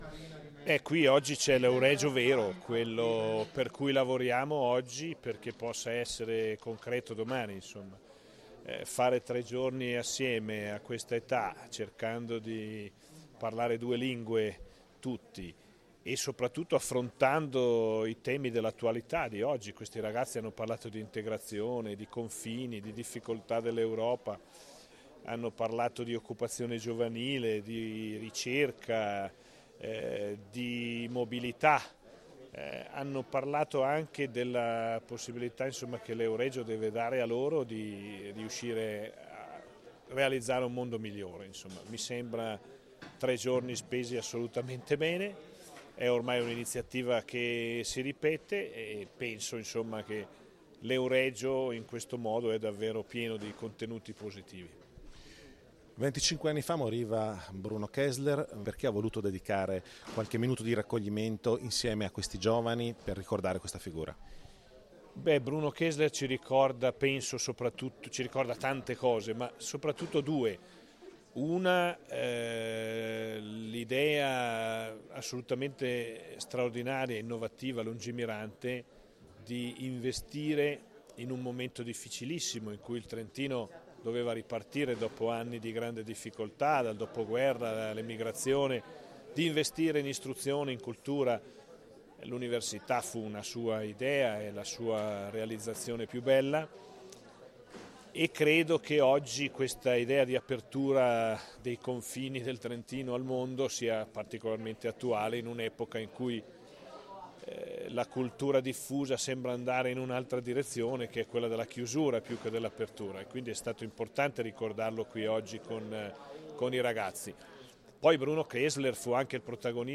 A Pergine Valsugana l’incontro con i giovani
int_rossi_euregio_MP3_256K.mp3